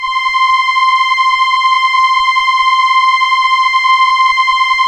Index of /90_sSampleCDs/Keyboards of The 60's and 70's - CD1/STR_Elka Strings/STR_Elka Cellos